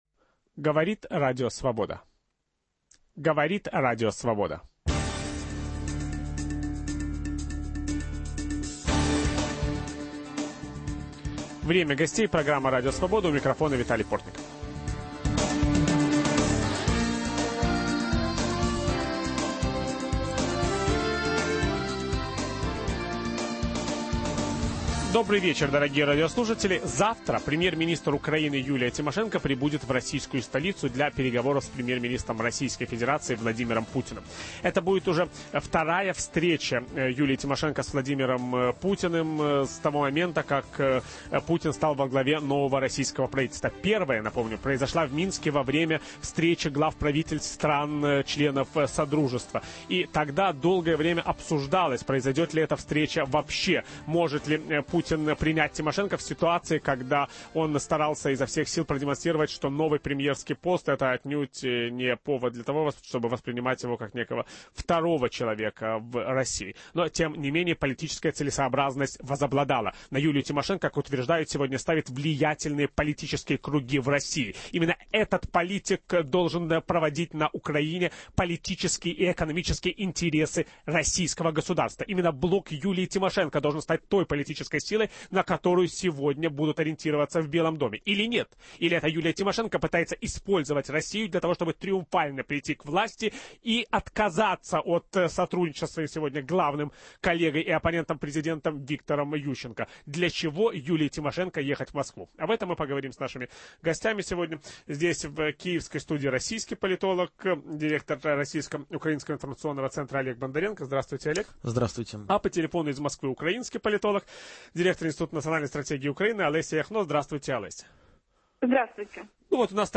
О чем она хочет договориться с Владимиром Путиным? Участвуют политологи